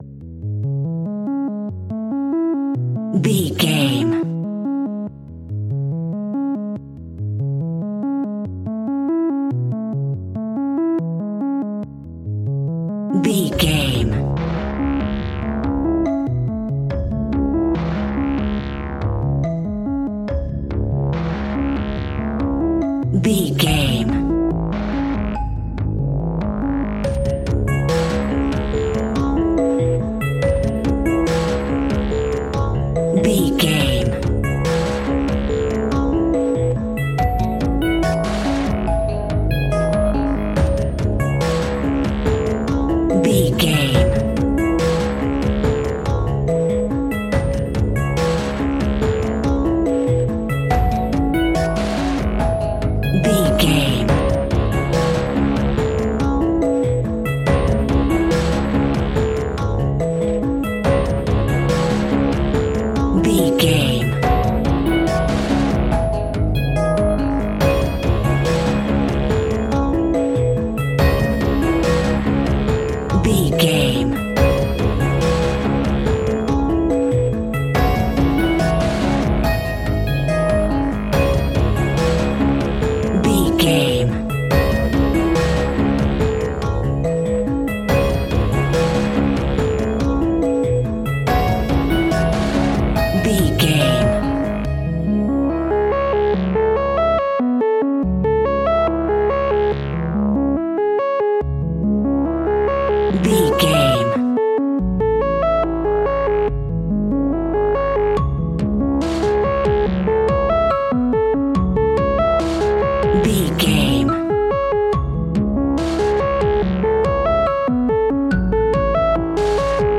A modern and futuristic style horror and suspense track.
Aeolian/Minor
D♭
suspense
piano
synthesiser